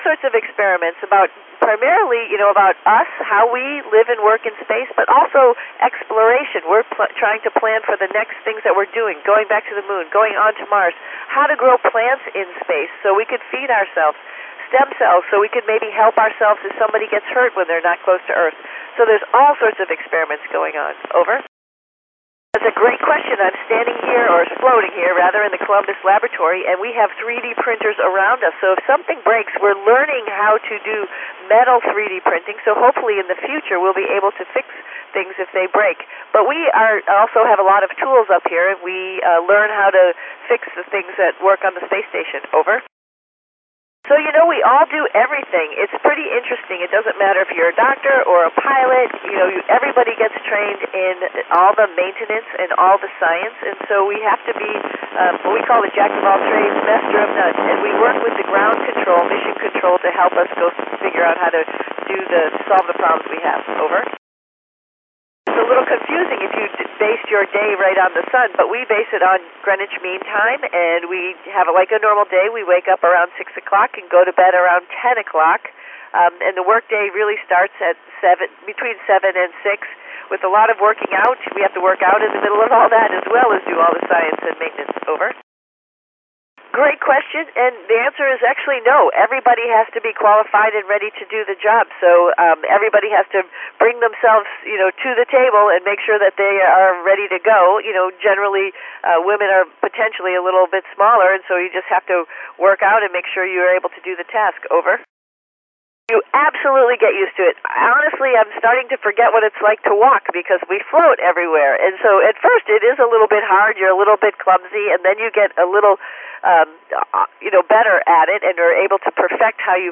On August 23rd 2024 at 08:05 UTC I monitored on 145.800 MHz in FM enclosed downlink signal of Sunita L. Williams KD5PLB using the callsign OR4ISS while being in contact with Gymnasium Meschede/Germany using the callsign DR0Z.
I recorded the answers #4 to #19.